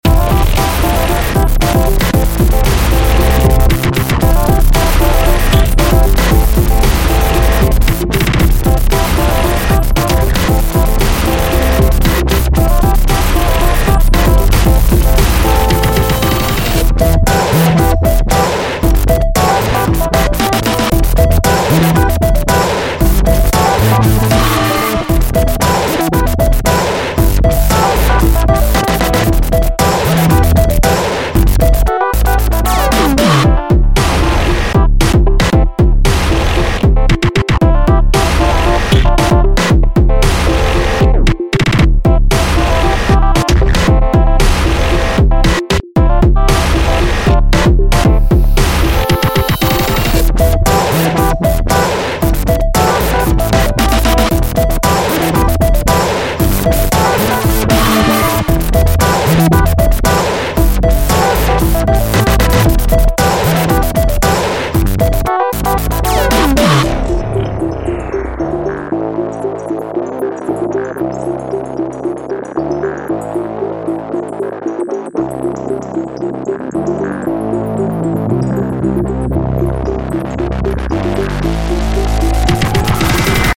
描述：舞蹈和电子音乐|明快
Tag: 合成器